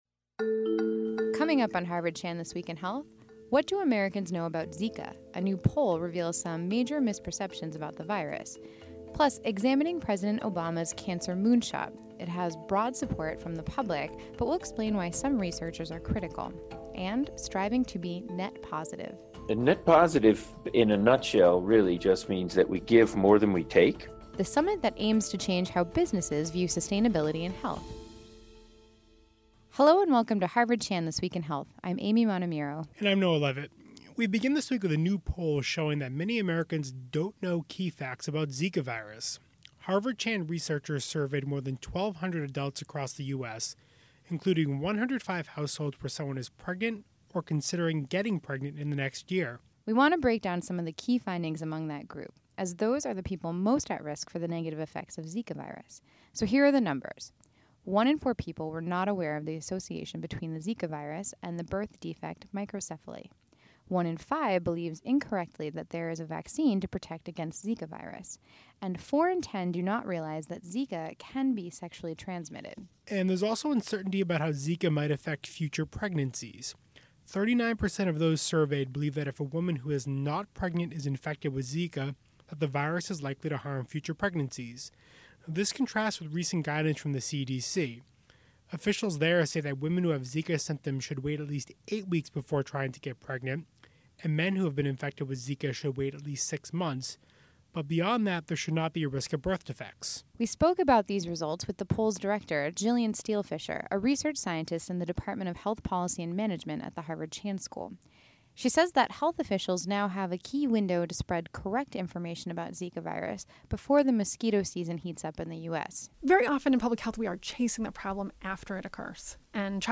Listen to press conferences on coronavirus (COVID-19) from the Harvard T.H. Chan School of Public Health, featuring experts in epidemiology, infectious diseases, environmental health, and health policy.